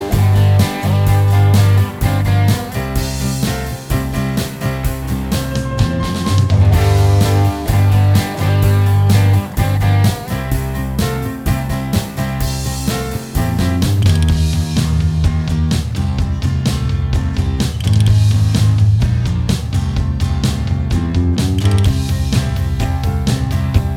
Minus Lead Guitar Rock 3:53 Buy £1.50